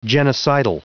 Prononciation du mot genocidal en anglais (fichier audio)
Prononciation du mot : genocidal